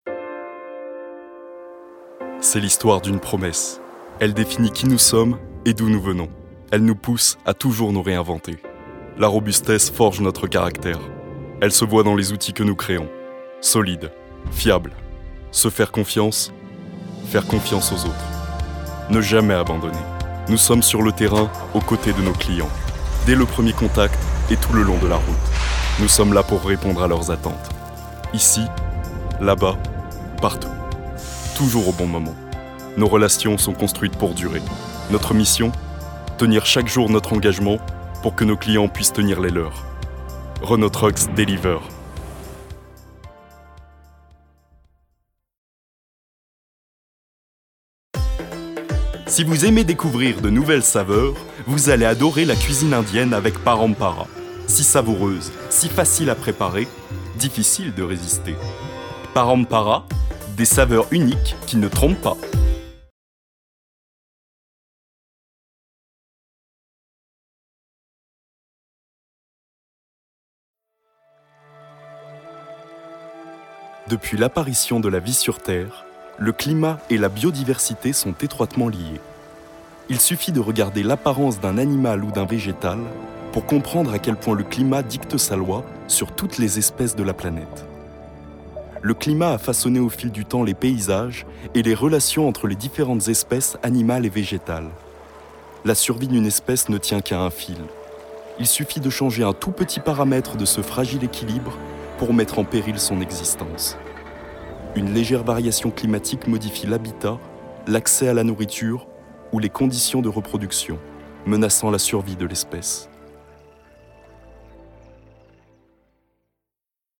Bande Démo voix
Voix off
25 - 35 ans - Basse